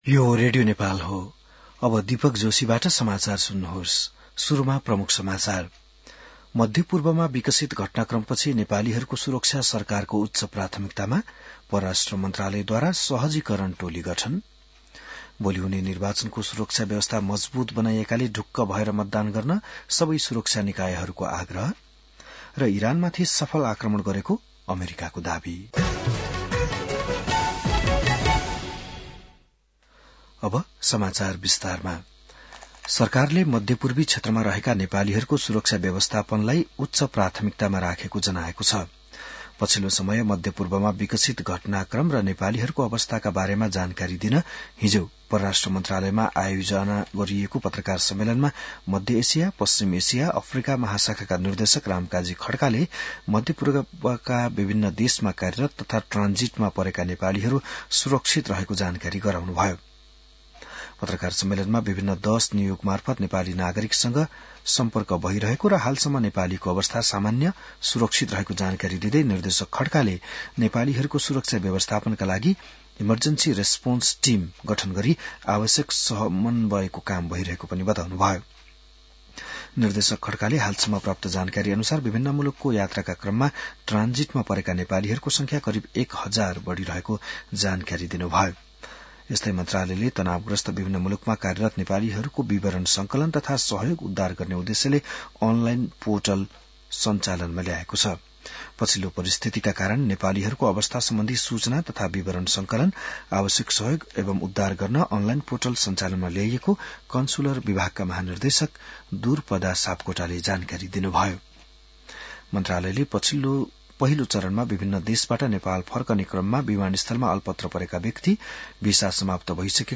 An online outlet of Nepal's national radio broadcaster
बिहान ९ बजेको नेपाली समाचार : २० फागुन , २०८२